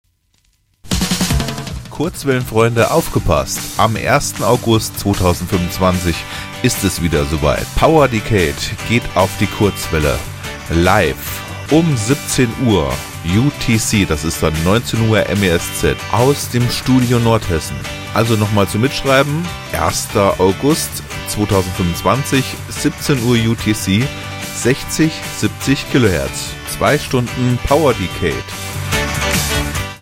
Hier der Werbespot: Werbung-PowerDecade010825.mp3